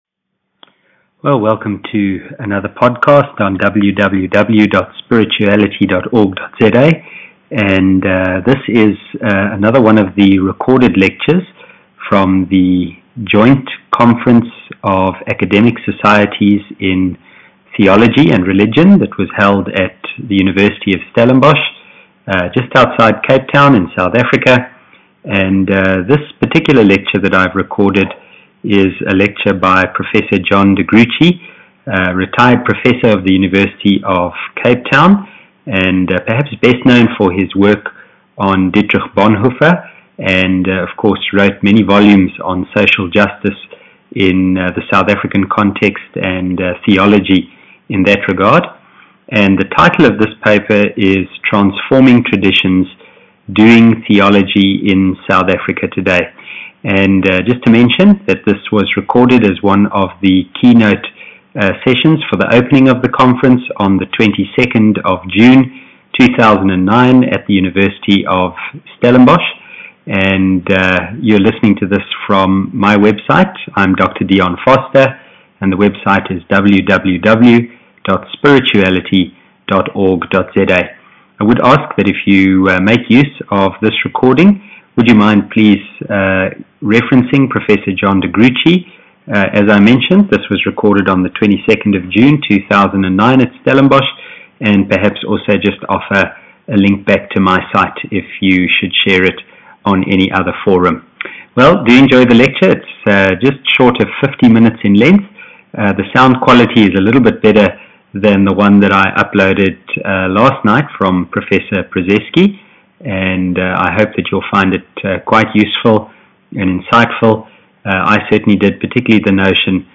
I recorded the lecture using my Macbook - so the sound quality is not all that great. It is not all that bad, but there were some instances when a few desks and chairs were moved in order to get some extra persons into the venue who arrived late.